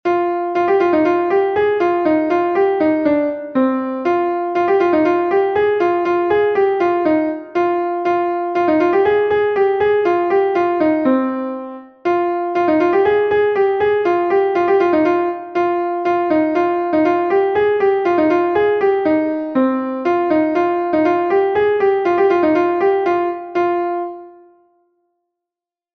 Gavotenn Leskoed est un Gavotte de Bretagne